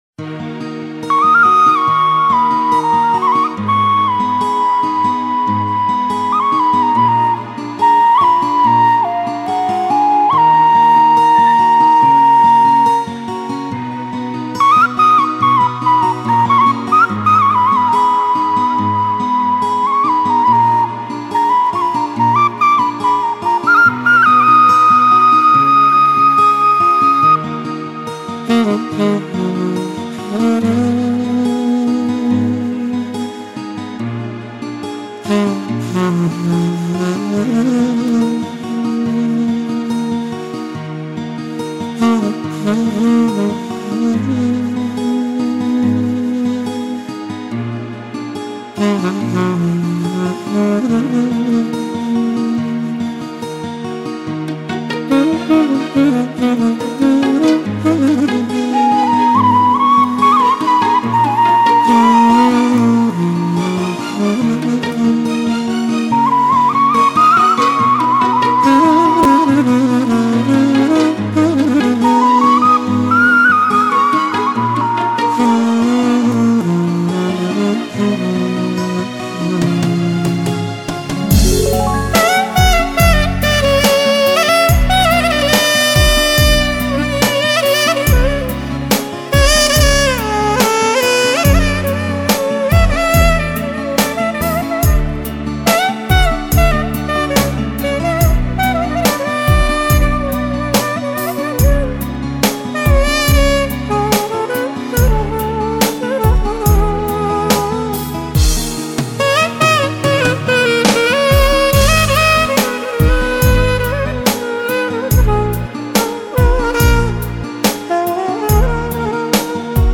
Саксафон, флейта, дудук ...